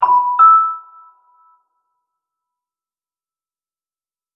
doorbell-tone-2864.wav